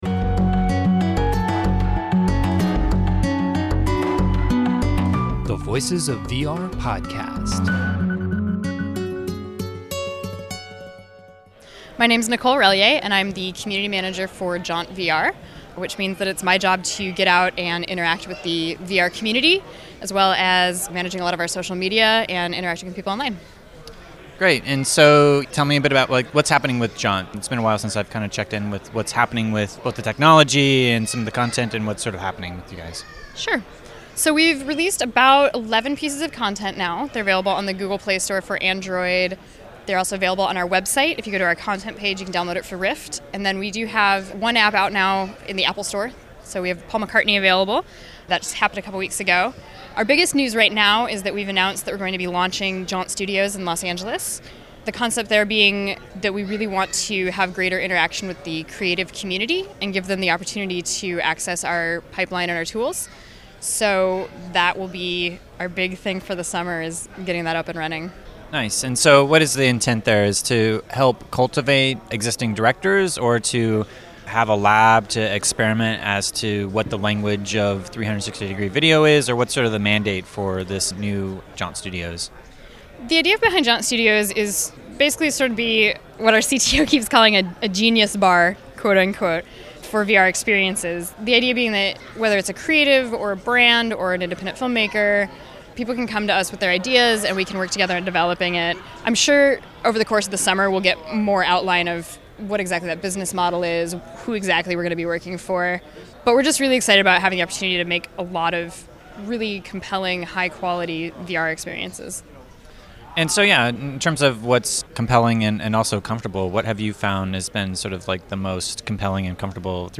One big announcement that Janut had after SVVRCon and after this interview was their Neo digital lightfield camera.